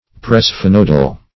Search Result for " presphenoidal" : The Collaborative International Dictionary of English v.0.48: Presphenoidal \Pre`sphe*noid"al\, a. (Anat.) Of or pertaining to the presphenoid bone; presphenoid.
presphenoidal.mp3